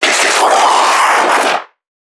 NPC_Creatures_Vocalisations_Infected [53].wav